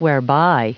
Prononciation du mot whereby en anglais (fichier audio)
Prononciation du mot : whereby